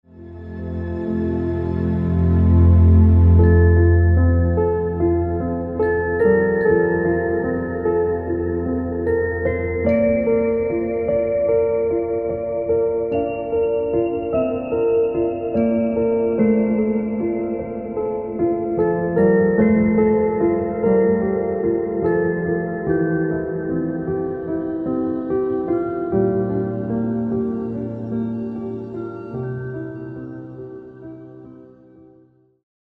Klang-Stimulation zur Aktivierung der Herzenergie